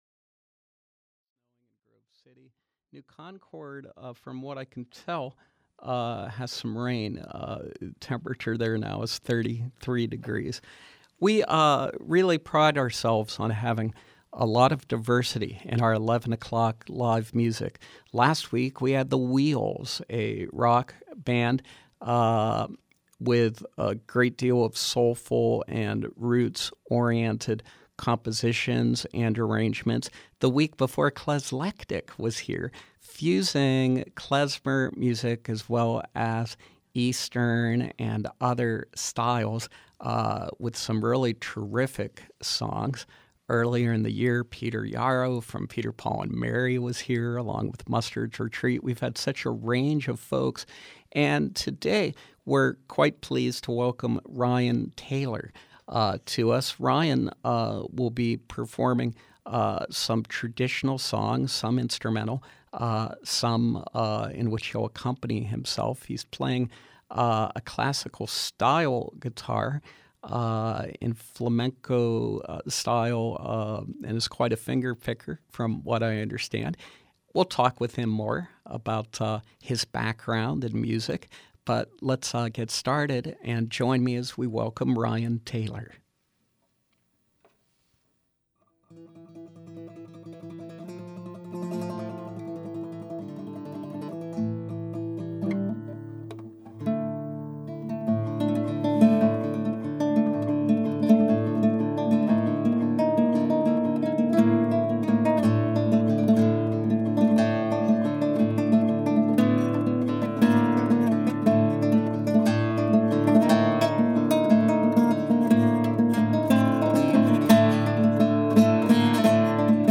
Traditional and contemporary folk songs
Flamenco-style fingerpicker